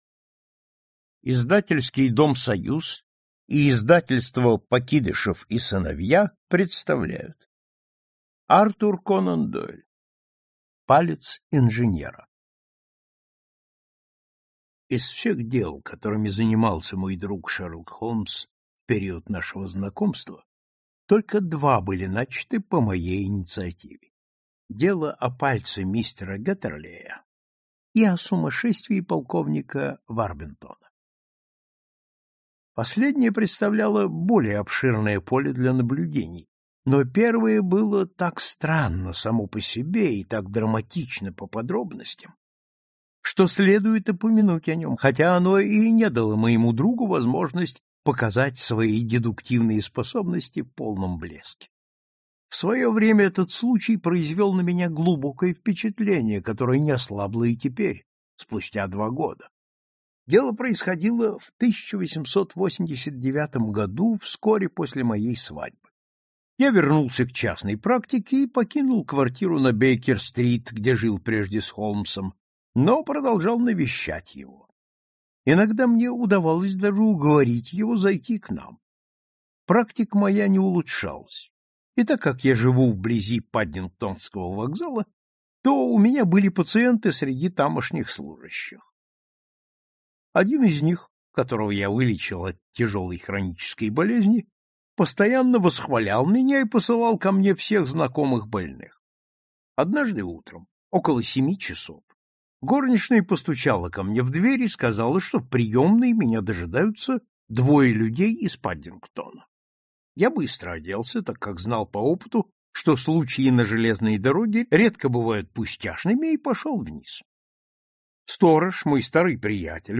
Аудиокнига Палец инженера | Библиотека аудиокниг